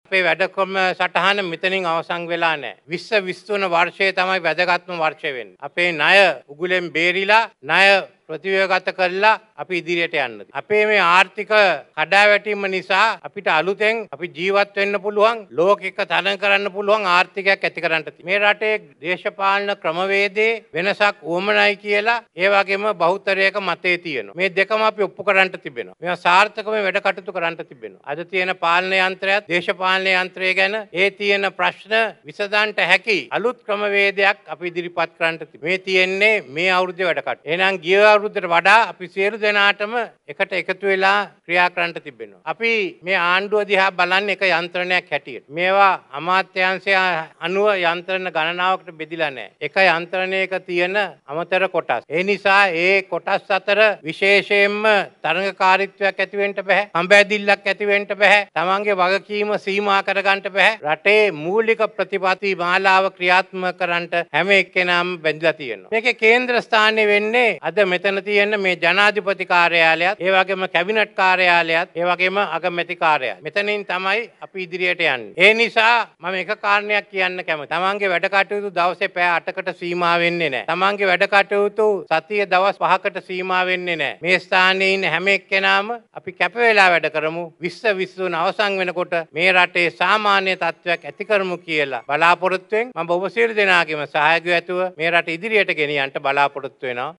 නව වසරේ රාජකාරී ආරම්භ කිරීම වෙනුවෙන් ජනාධිපති කාර්යාලයේ පැවති විශේෂ වැඩසටහනට සහභාගී වෙමින් ජනාධිපතිවරයා මේ බව ප්‍රකාශ කළා.
නව වසරේ රාජකාරී ආරම්භ කිරීම වෙනුවෙන් විශේෂ උත්සවයක් අද පෙරවරු 09.00 ට ජනාධිපති ලේකම් කාර්යාලය ඉදිරිපිට පැවැත්වුනා.